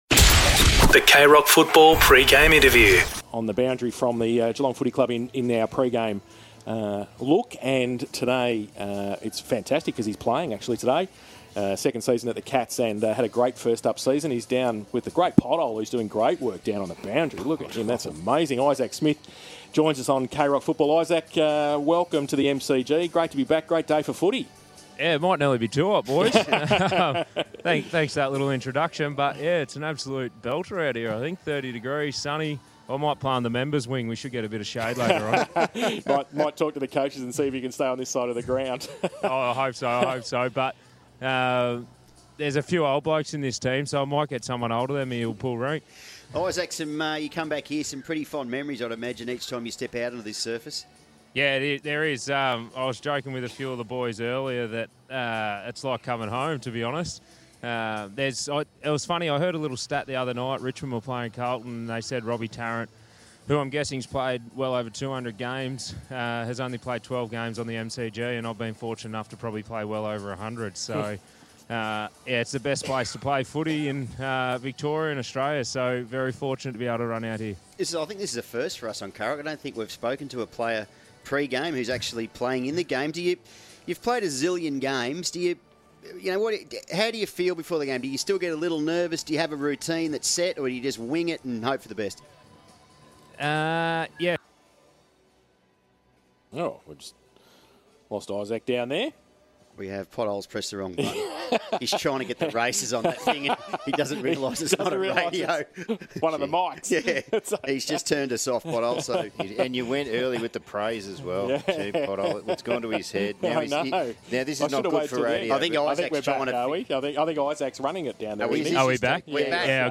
2022 - AFL ROUND 1 - GEELONG vs. ESSENDON: Pre-match Interview - Isaac Smith (Geelong)